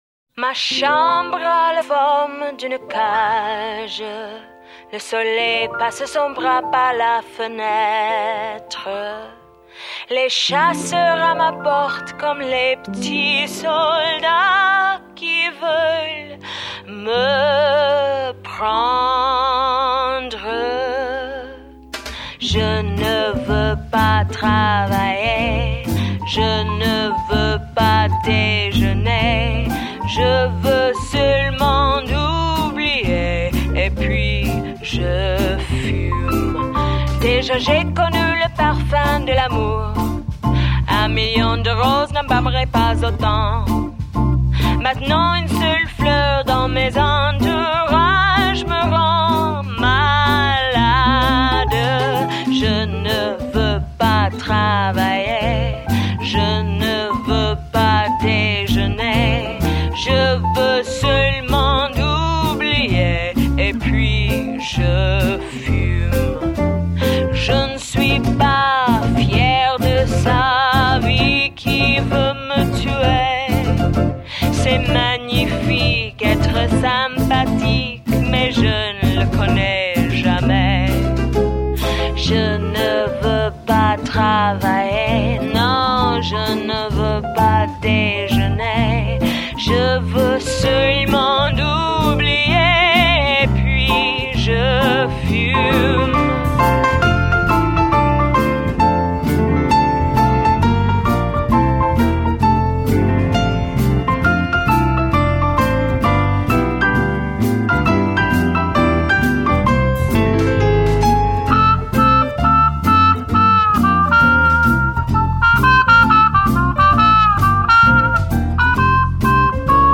歌，有点留声机的味道。